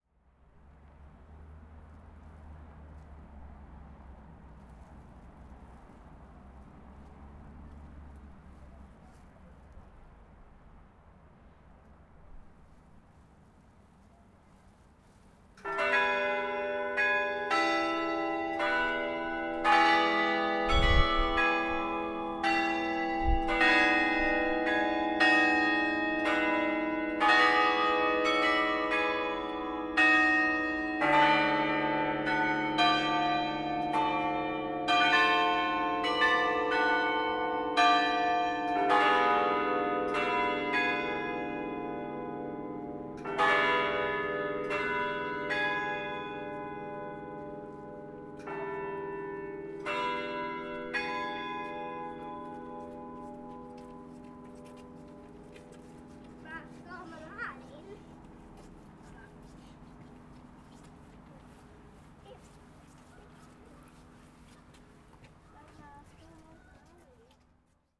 Kiruna kvällsringning i stadshustornet
Klocktornets ringningar fortsätter att karaktärisera ljudmiljön i centrum av Kiruna, där det invigdes efter flytten [meer]
Klocktornet-Short.wav